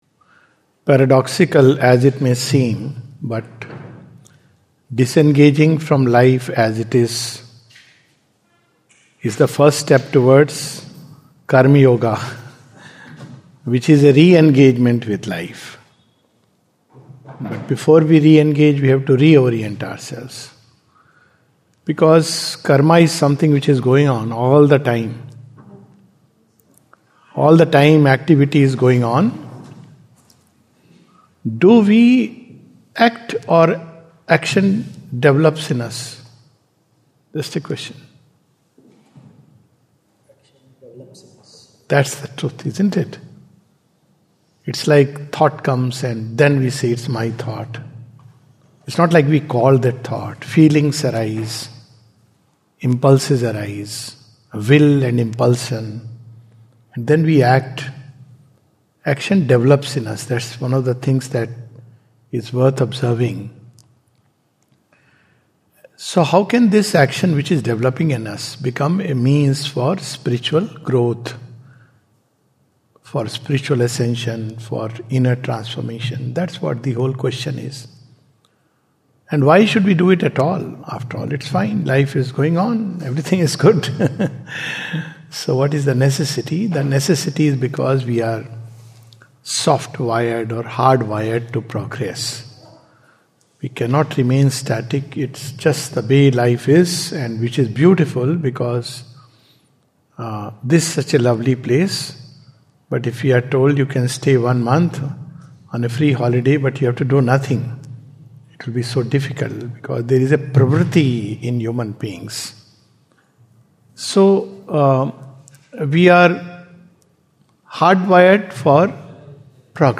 This is a talk